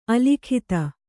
♪ alikhita